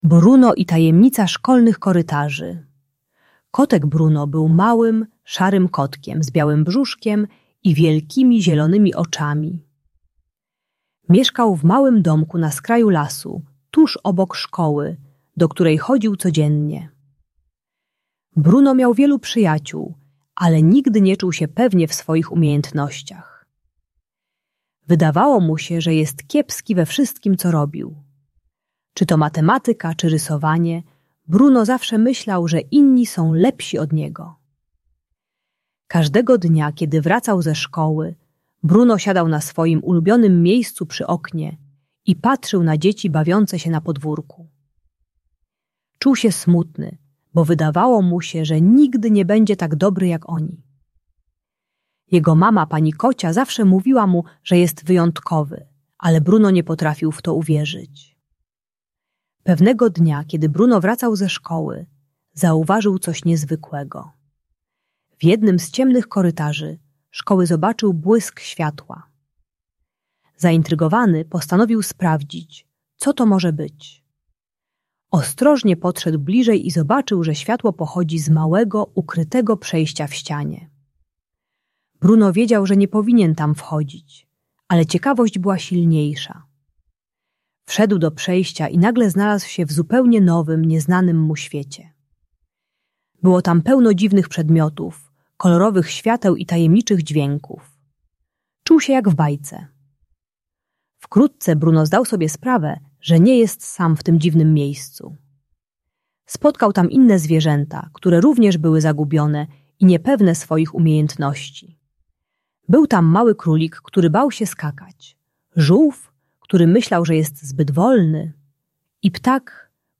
Bruno i Tajemnica Szkolnych Korytarzy - Lęk wycofanie | Audiobajka